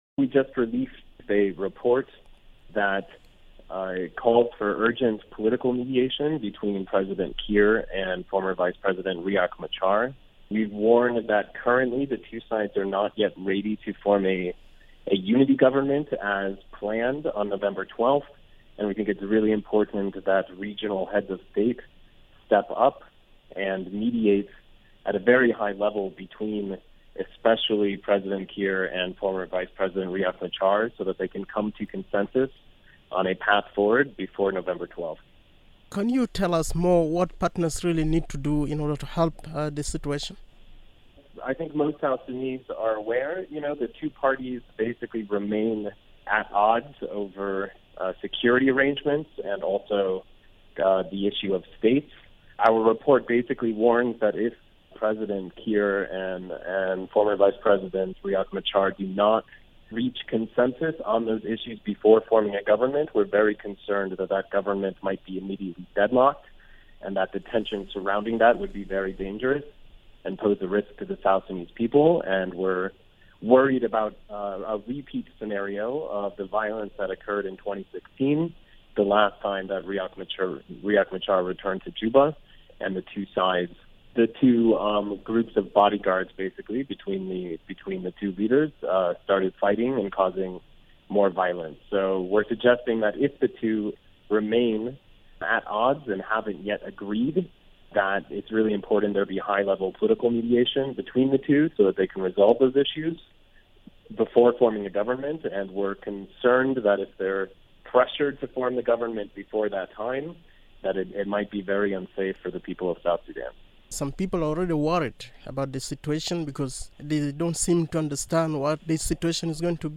He spoke to radio Miraya in the following interview